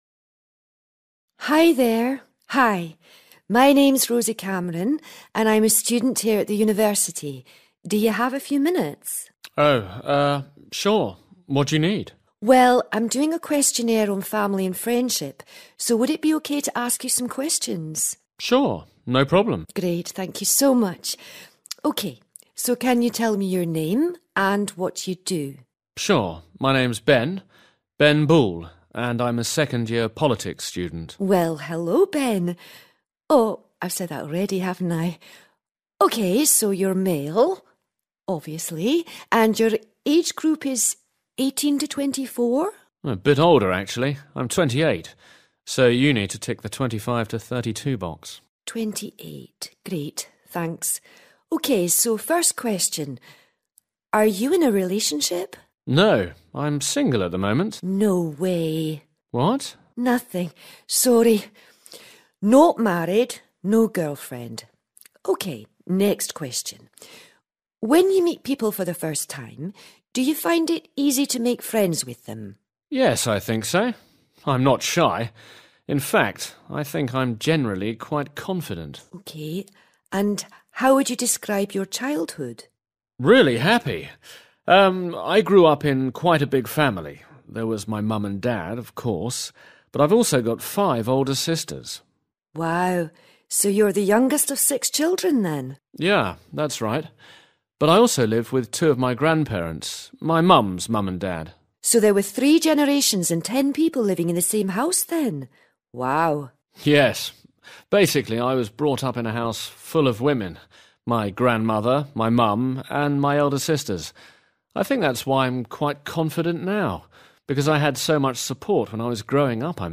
A   Listen to part of a conversation between two students at a university.